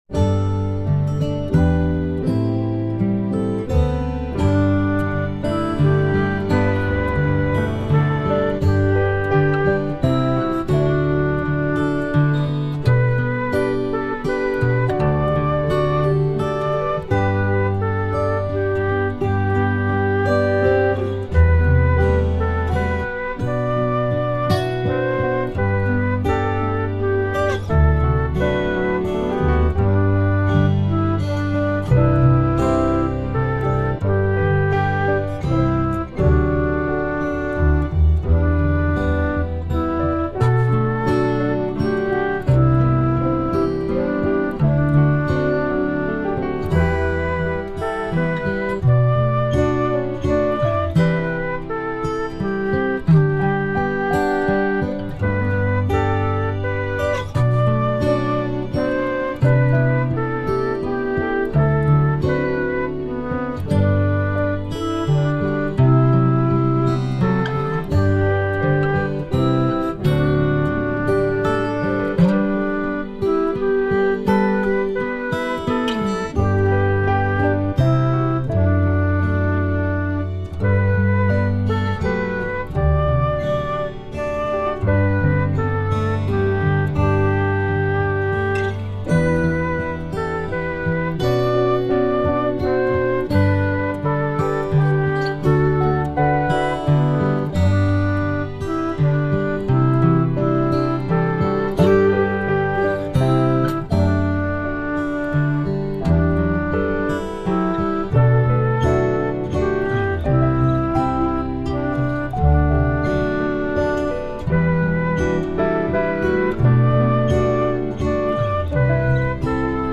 I’m sorry – I made up some chords: